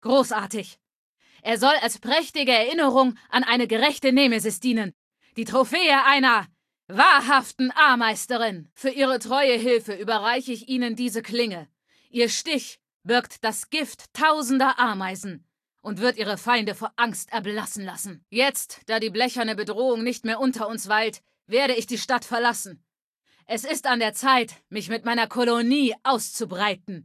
Datei:Femaleadult01default ms02 ms02suityes 00098ccd.ogg
Fallout 3: Audiodialoge